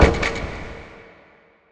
c_spiker_hit3.wav